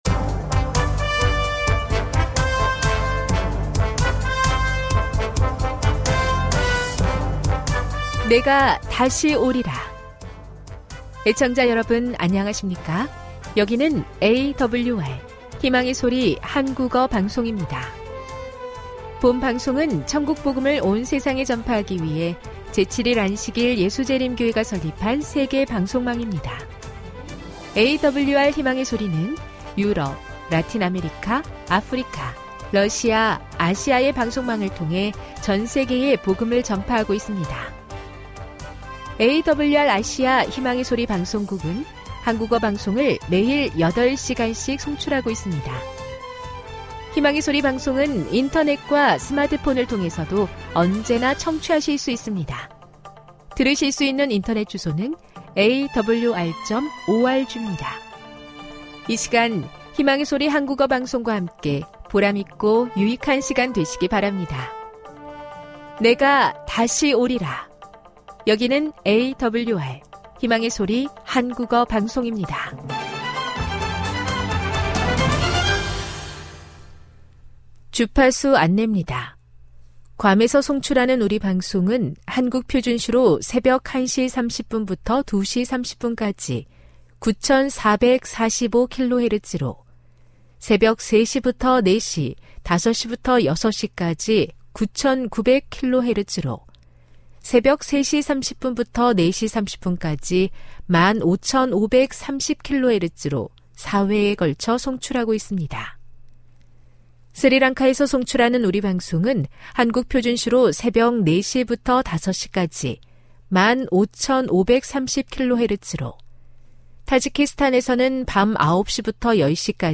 1 설교, 난해 성경절해설 58:31